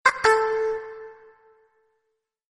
Vypočuje si upozornenie aplikácie ICQ:
Vyzváňanie aplikácia ICQ
IcqSms.mp3